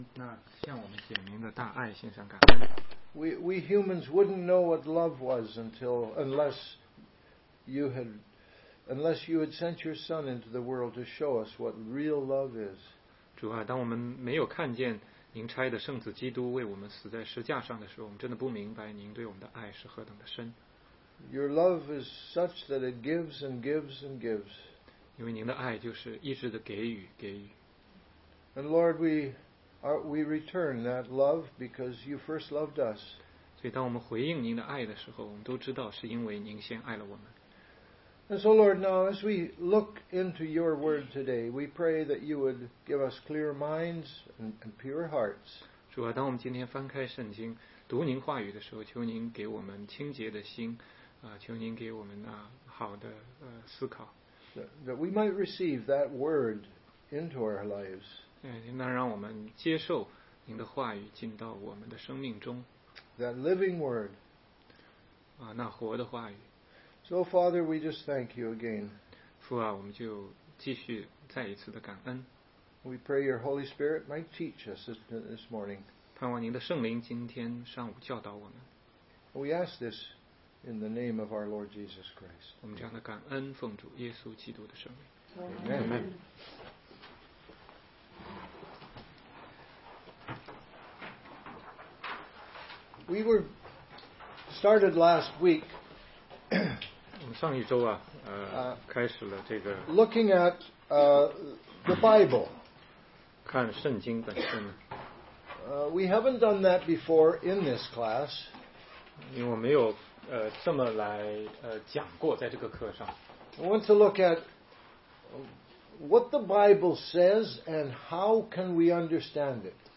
16街讲道录音 - 怎样才能读懂圣经系列之二